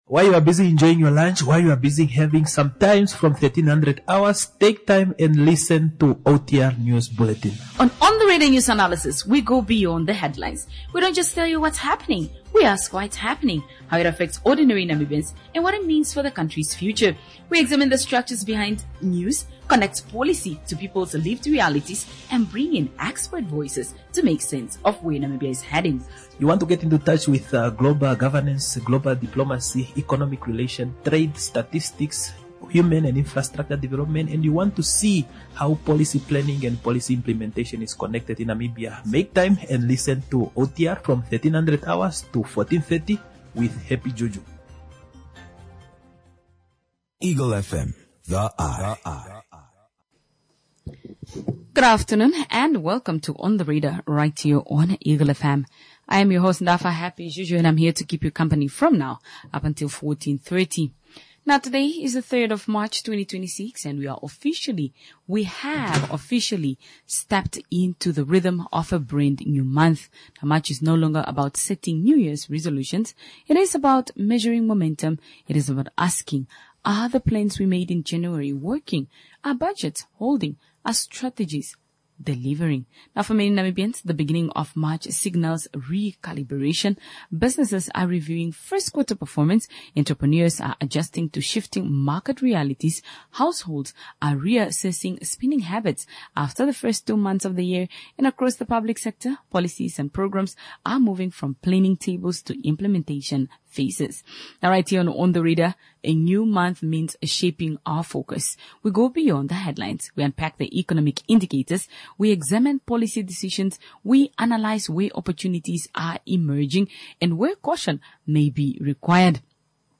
A through discussion